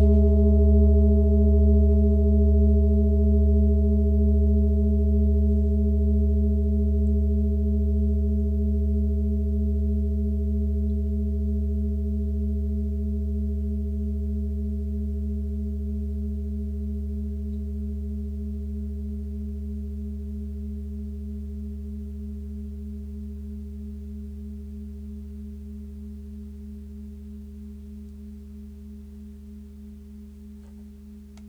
Klangschale Nepal Nr.52
Klangschale-Durchmesser: 29,5cm
(Ermittelt mit dem Filzklöppel)
Der Marston liegt bei 144,72 Hz, das ist nahe beim "D".
klangschale-nepal-52.wav